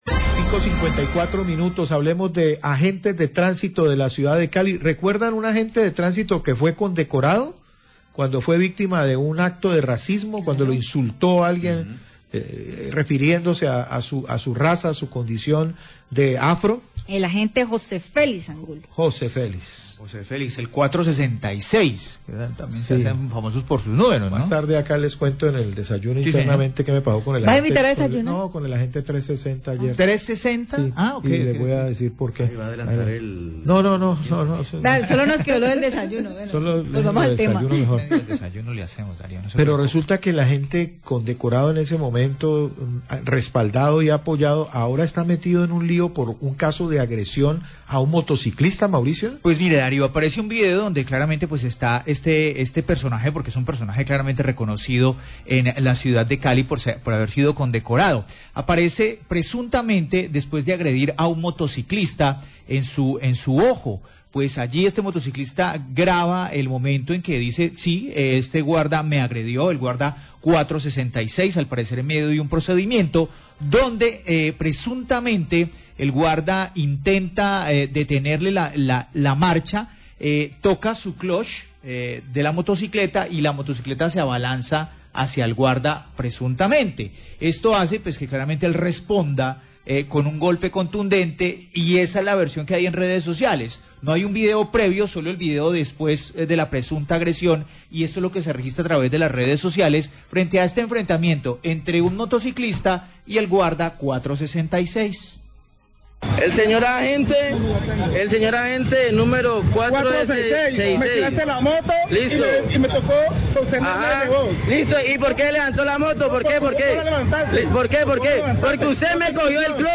Radio
Periodista comenta sobre los reiterados casos de intolerancia entre conductores y agentes de tránsito en Cali.